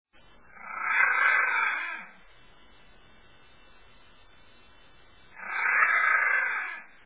ツシマヤマネコの鳴き声
センターで録音した「ツシマヤマネコの鳴き声」を公開します。
発情期の声